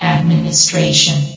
S.P.L.U.R.T-Station-13/sound/vox_fem/administration.ogg
* New & Fixed AI VOX Sound Files